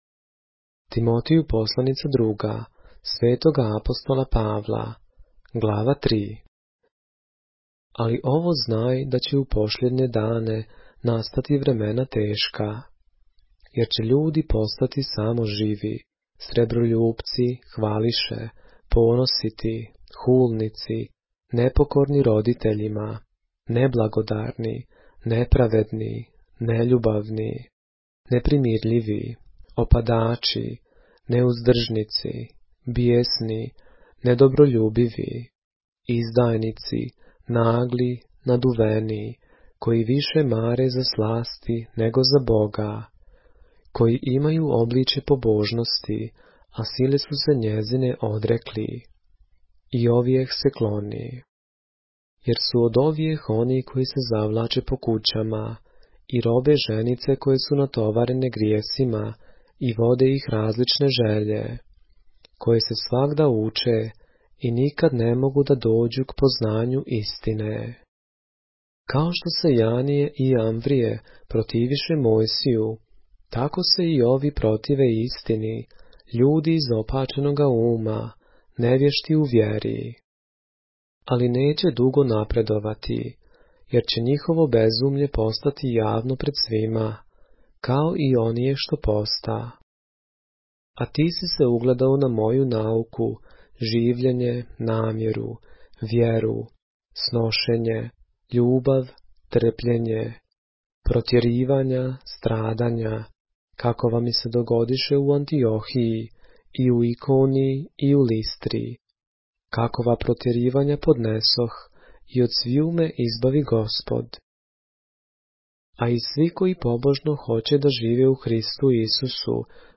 поглавље српске Библије - са аудио нарације - 2 Timothy, chapter 3 of the Holy Bible in the Serbian language